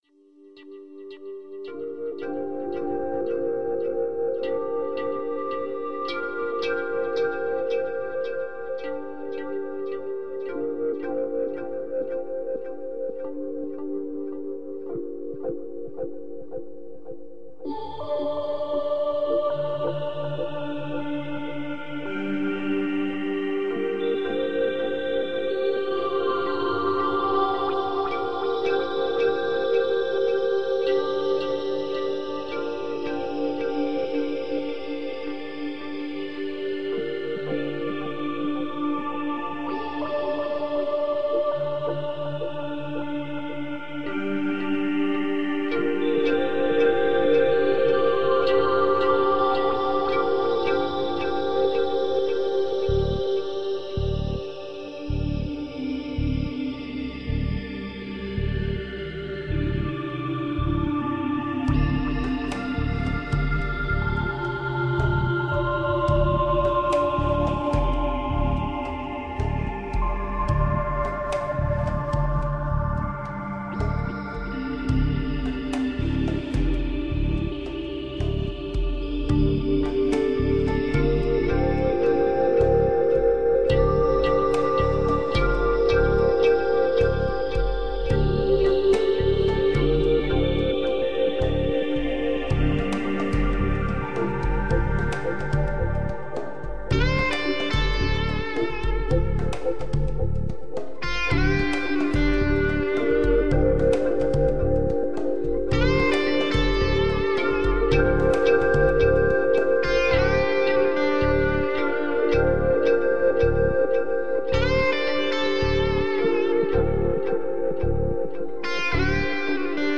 newage sounds to enlighten and uplift.